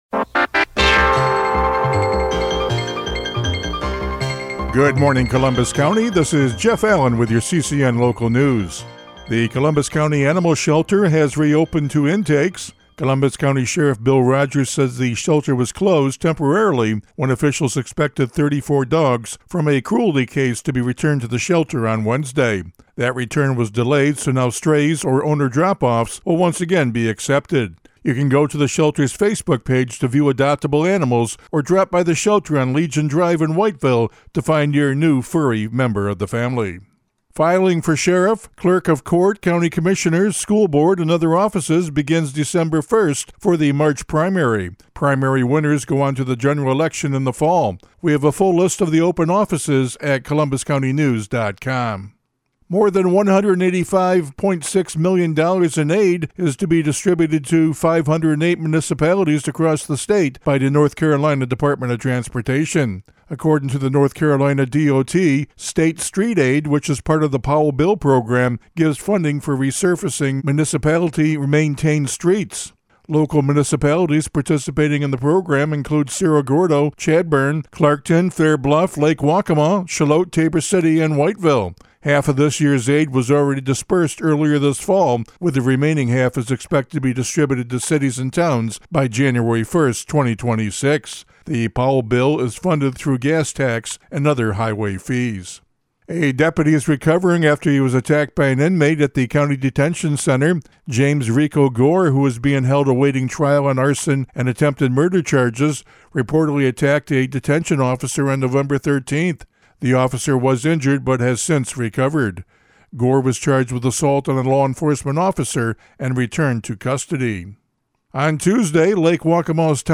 CCN Radio News — Morning Report for November 20, 2025